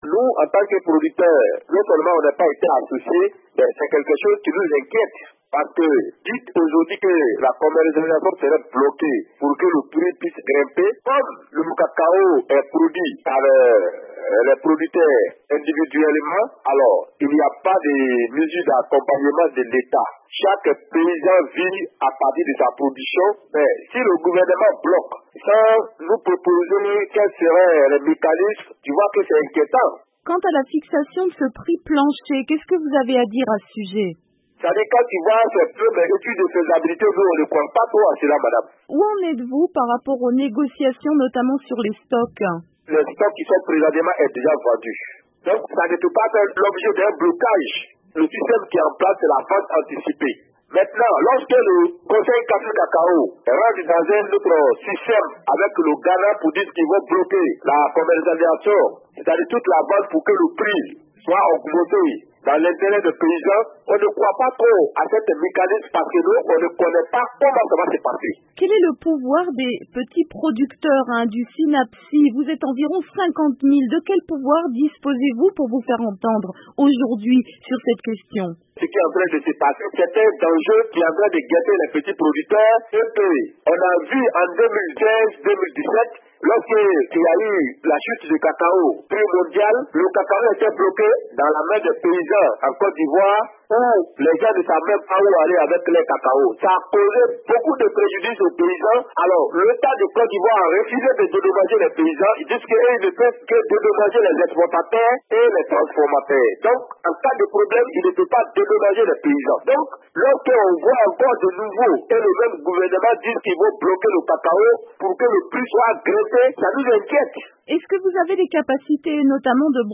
réagit au micro de VOA Afrique